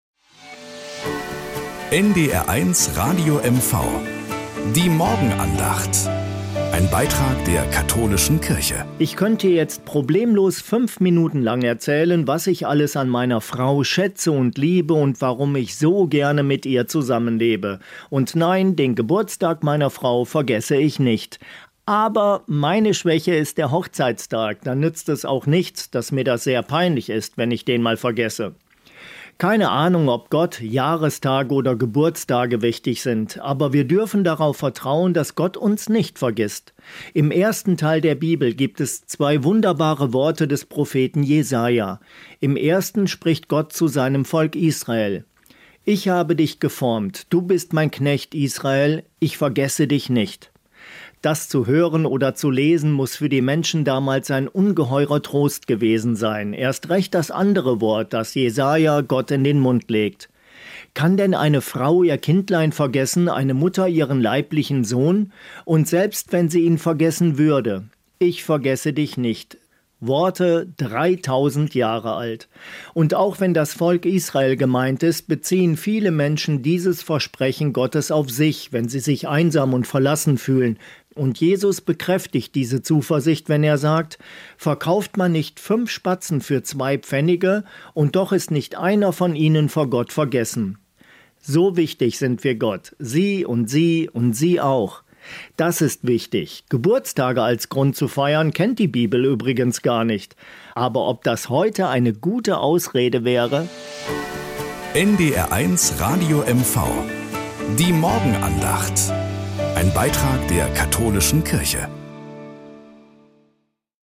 Nachrichten aus Mecklenburg-Vorpommern - 13.05.2025